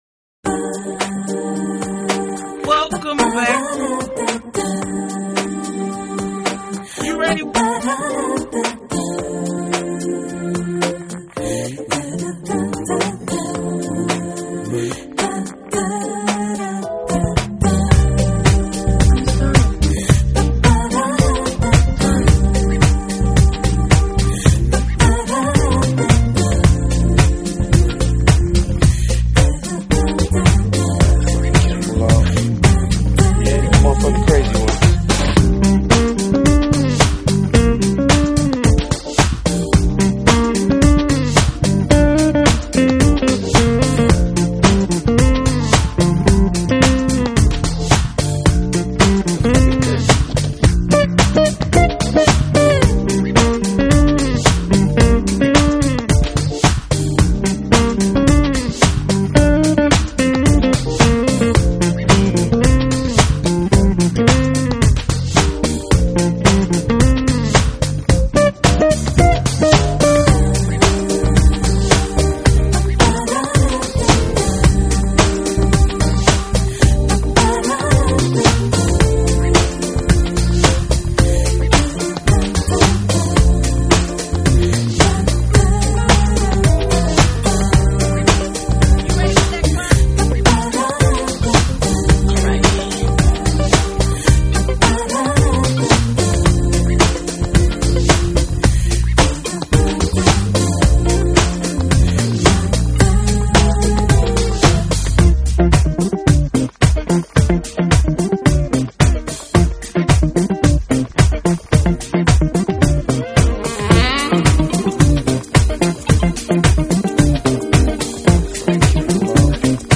音乐风格: Smooth Jazz / Lounge
并且融合了pop/funk/latin的节奏。
听了一个礼拜多，我很喜欢北欧的Jazz，有点冷但不失炫。